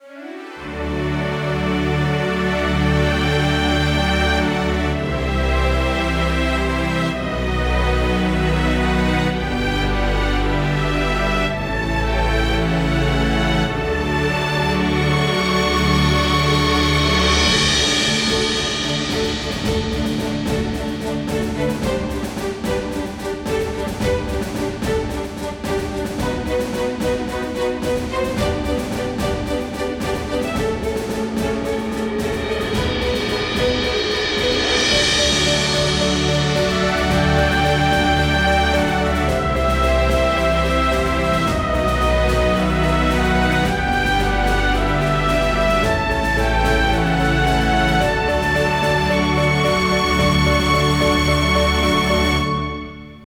Game Music
Motivational Musics for Kid’s game